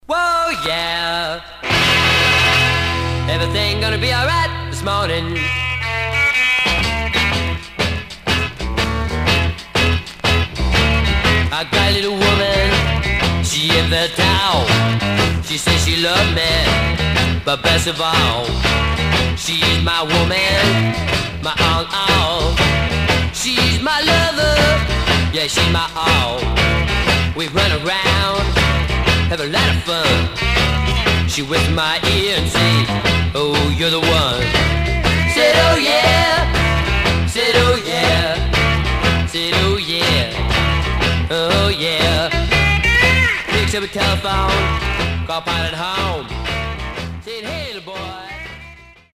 Stereo/mono Mono
Garage, 60's Punk Condition